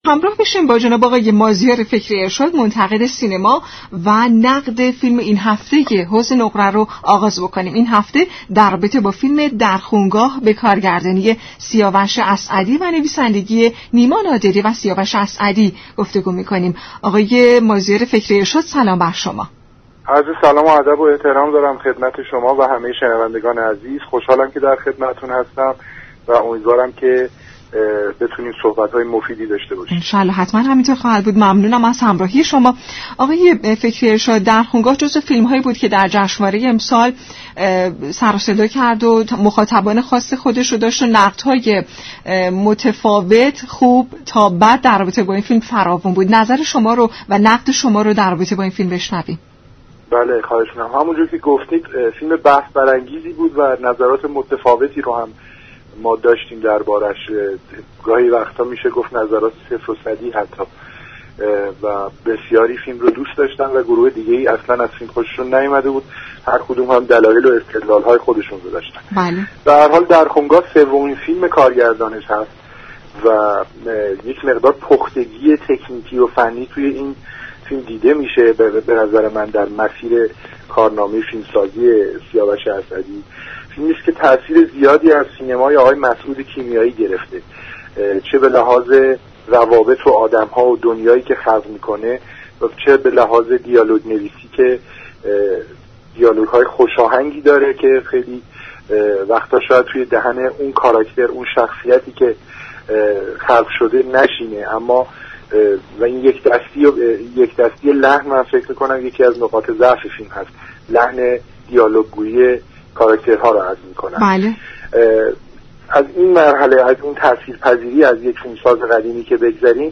در گفت و گو با رادیو ایران